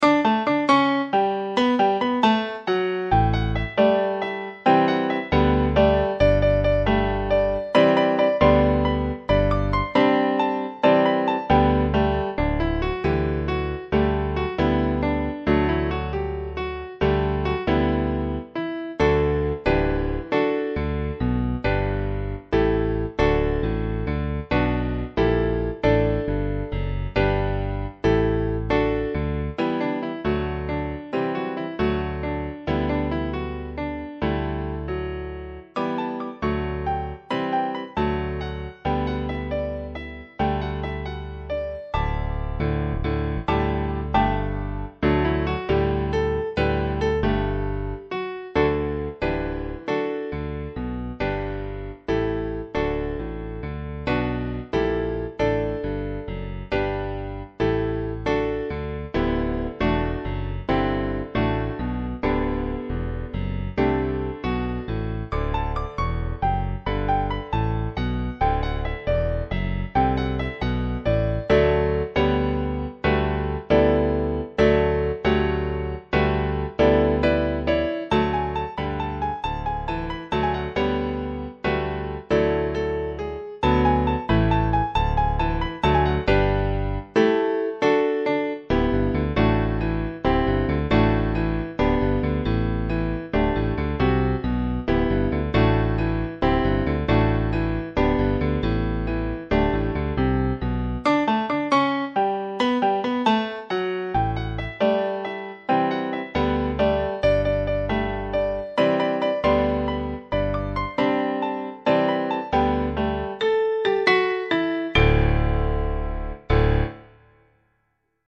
base2 (solo pf.)